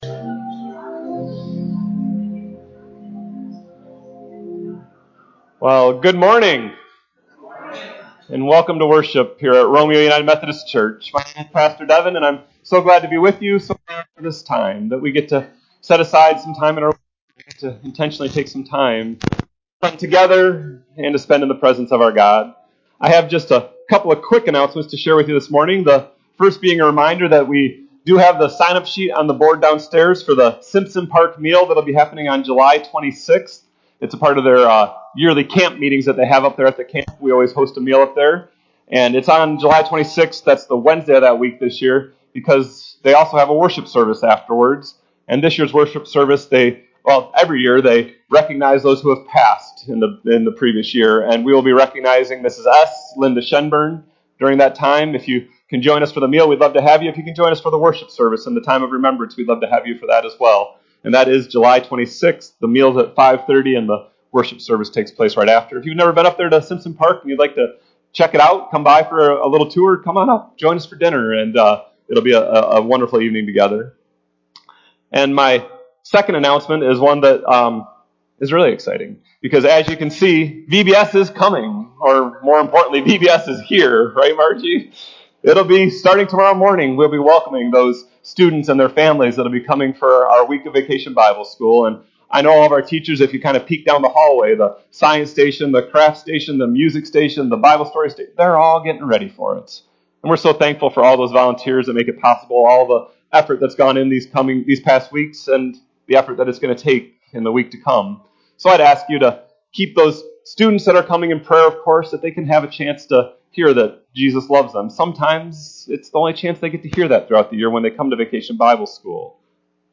RUMC-service-July-9-2023-CD.mp3